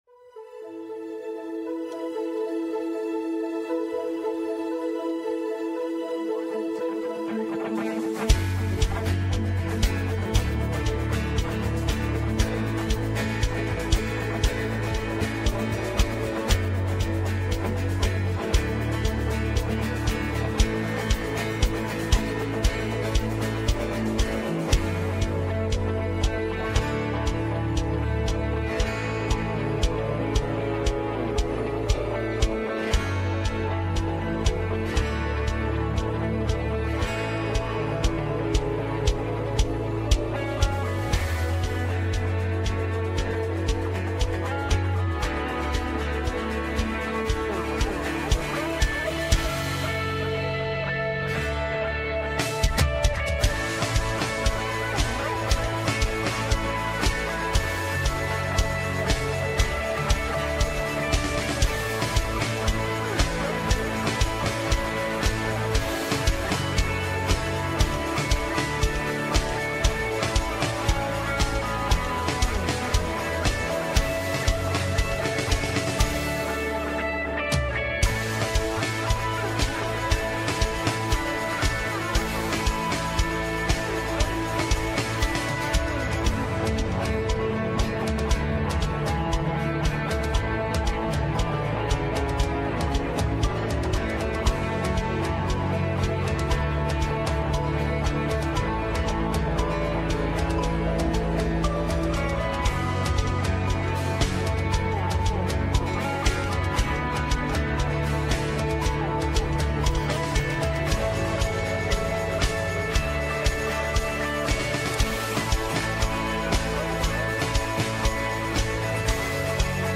Westgate Chapel Sermons